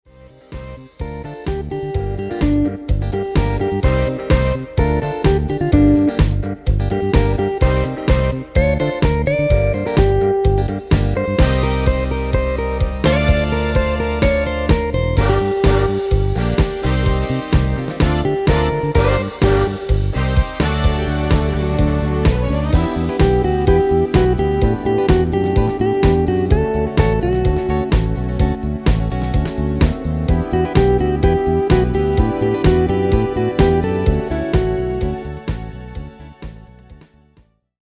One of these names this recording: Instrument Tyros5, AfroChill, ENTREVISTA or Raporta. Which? Instrument Tyros5